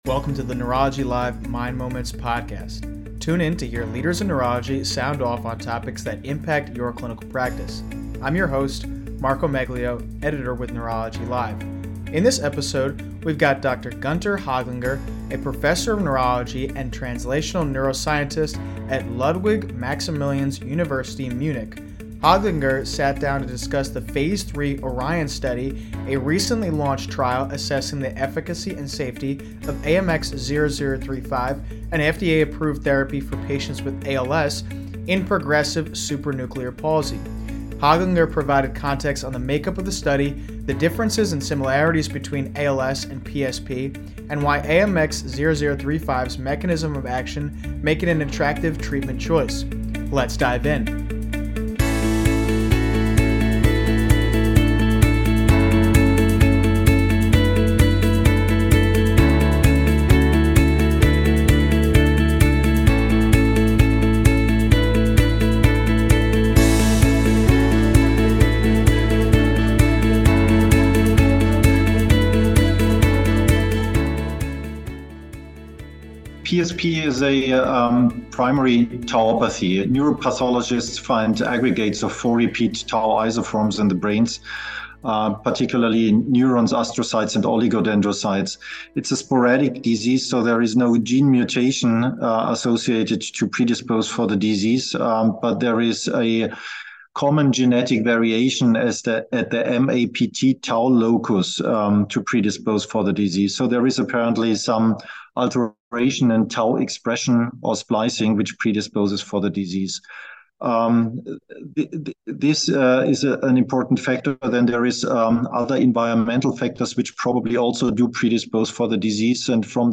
Tune in to hear leaders in neurology sound off on topics that impact your clinical practice.